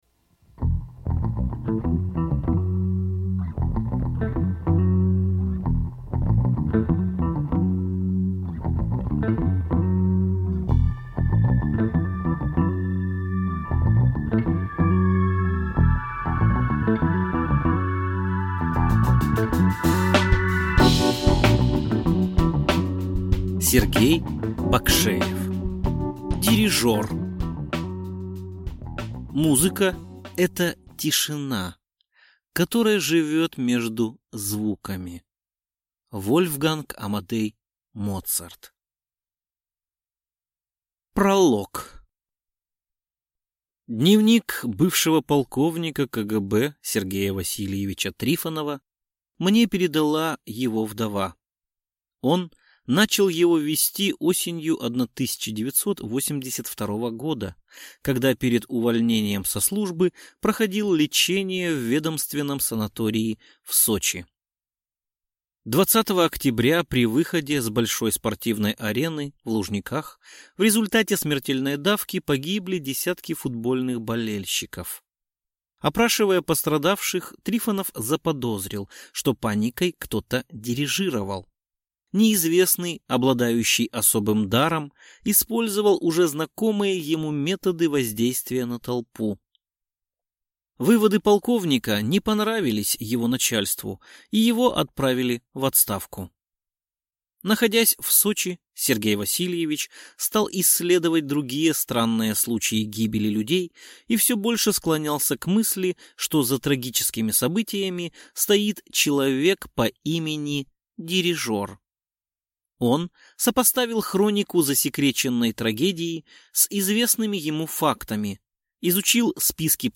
Аудиокнига Дирижер | Библиотека аудиокниг